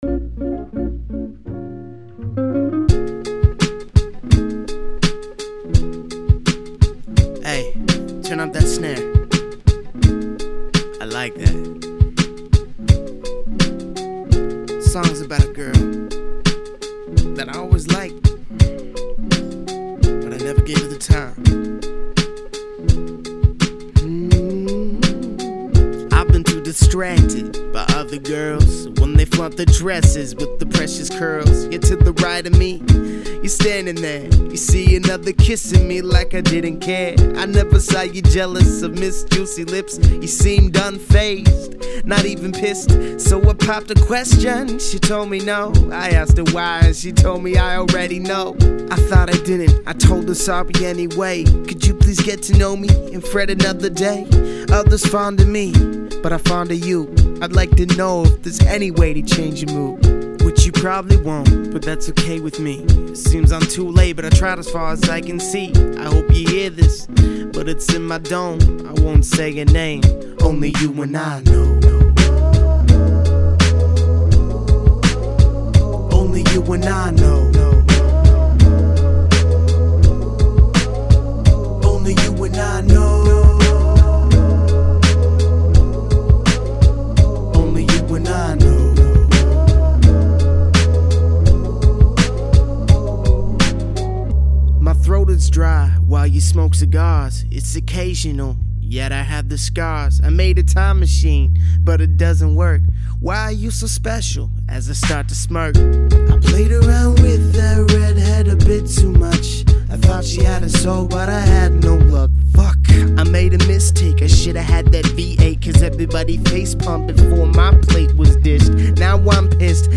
local hip-hop artists